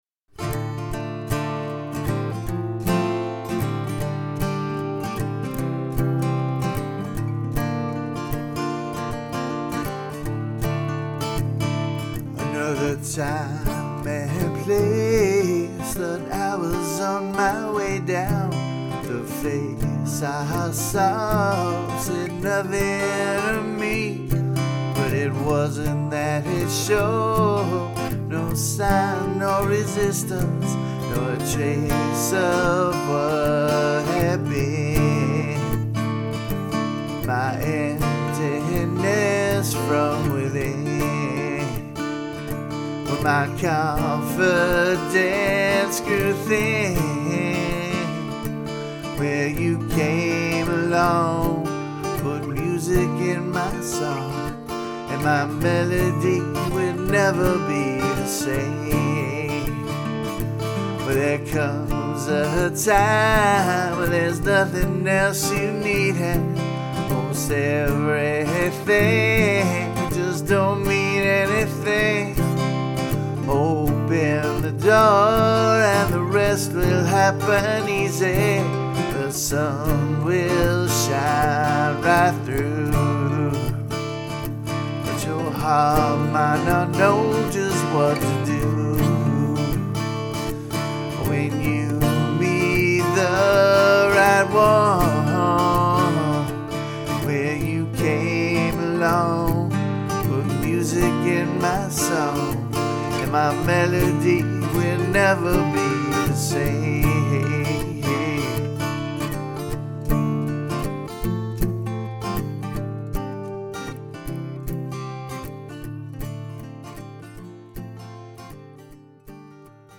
MeatbirdSoloAcousticDemo.mp3